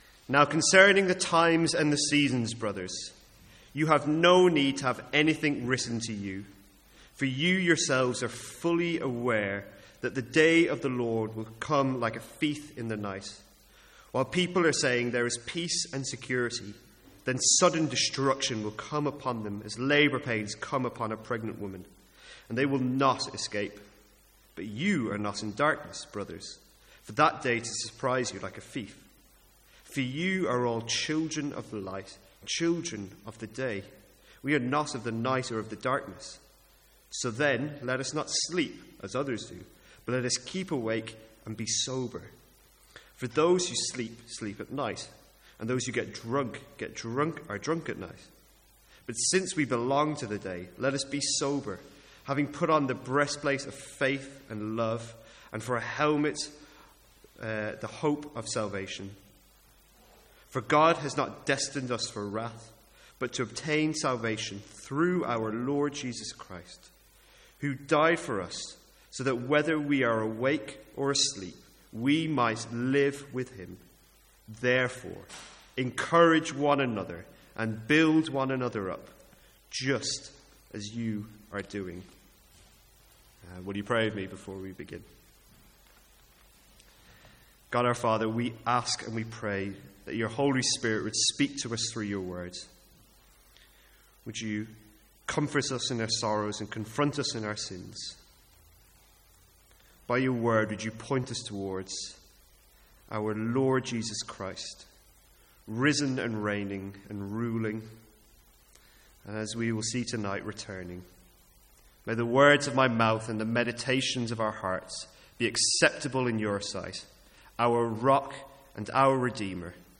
Sermons | St Andrews Free Church
From the Sunday evening series in 1 Thessalonians.